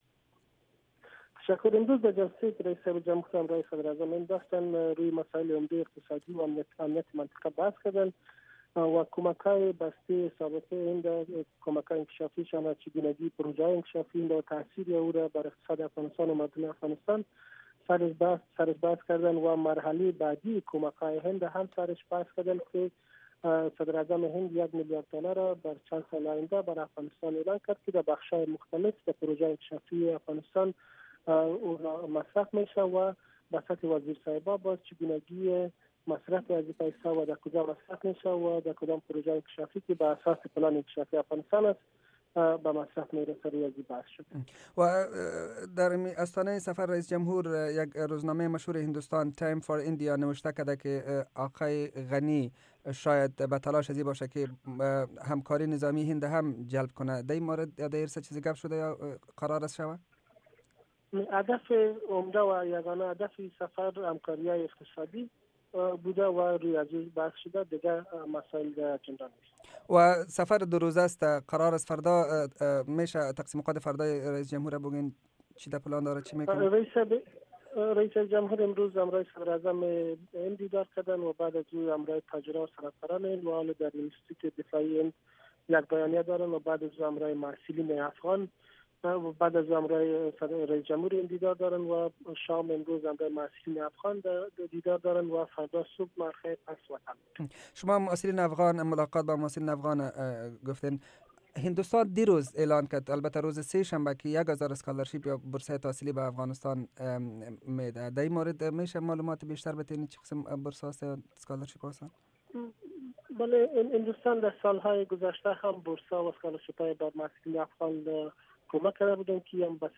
مصاحبه صدای امریکا با هارون چخانسوری، سخنگوی رئیس جمهور غنی
مصاحبه صدای امریکا با هارون چخانسوری، سخنگوی رئیس جمهور غنی در مورد سفر آقای غنی به هند